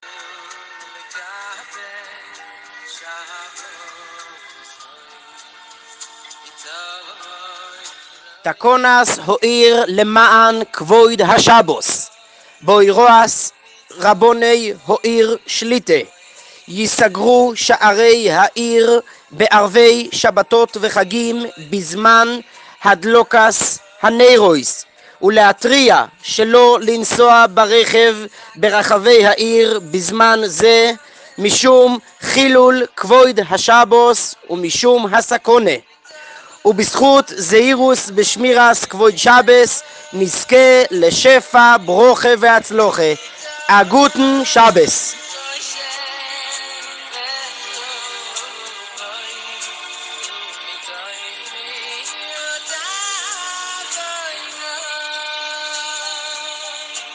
בהודעה טלפונית שנשלחה לכל תושבי העיר על התחבורה בכניסת השבת נאמר לקול צלילי “וזכינו לקבל שבתות מתוך רוב שמחה”: